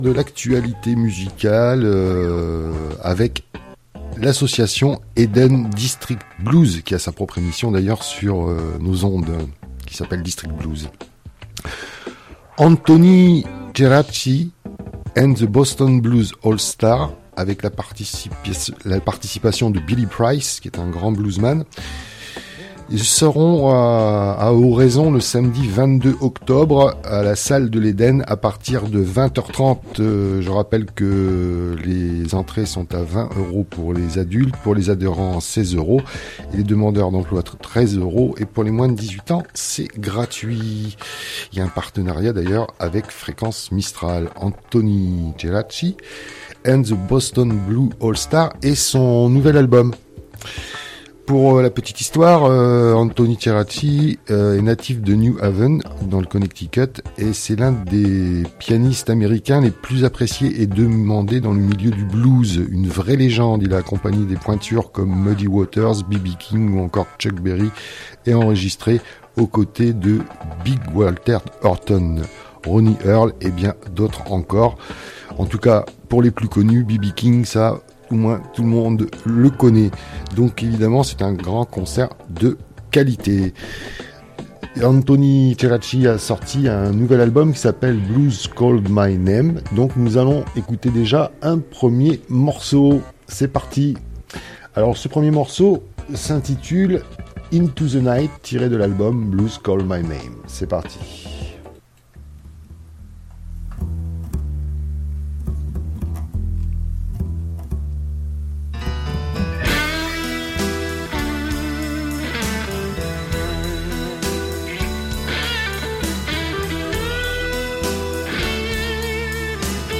Entre blues songs et instrumentaux
le blues, le boogie, le rhythm’n’blues
artiste de blues exeptionnel avec son groupe